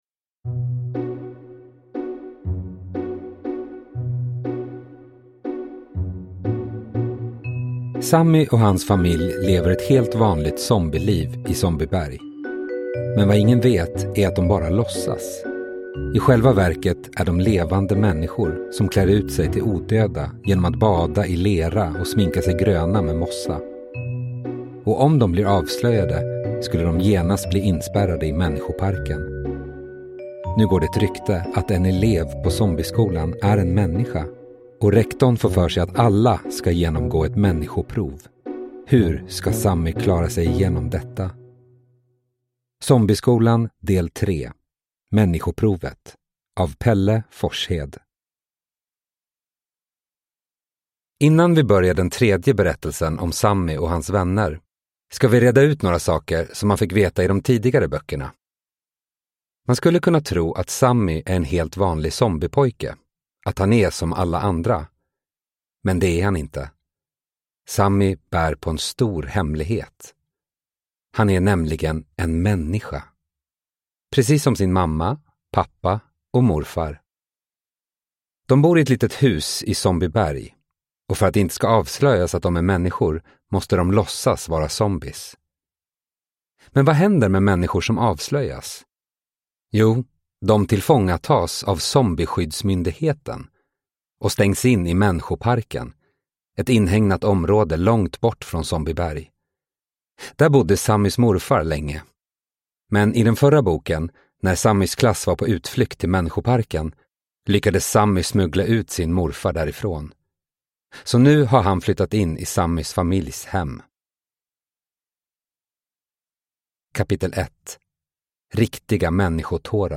Människoprovet – Ljudbok